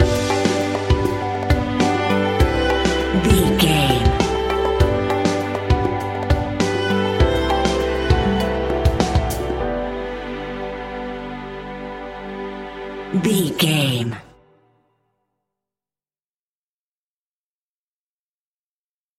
Ionian/Major
pop
fun
energetic
uplifting
cheesy
instrumentals
indie pop rock music
upbeat
rocking
groovy
guitars
bass
drums
piano
organ